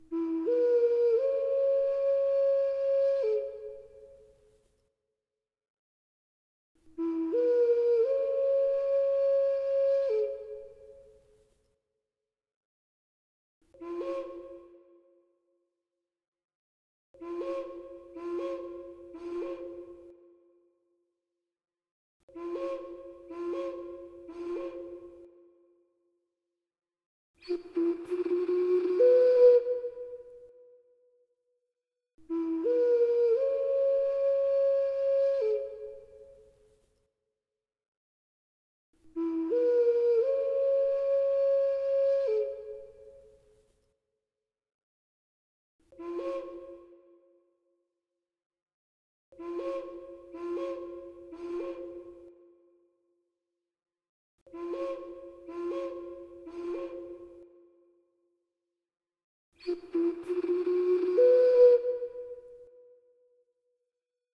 Instalación: Vidrio soplado en borosilicato verde, soportes metálicos y sonido. Dimensiones variables
Instrumento musical aerófono. Está inspirado en una mezcla de objetos como las ocarinas, cuco y algunos juguetes sonoros de viento: la panícula de maíz, el supriño o los silbatos de hoja en los que su decoración o materialidad representan aspectos relacionados a la naturaleza y las plantas o en algunos casos las hojas y las cortezas se utilizan para crear sonidos o diseñar instrumentos.